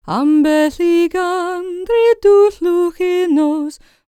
L CELTIC A30.wav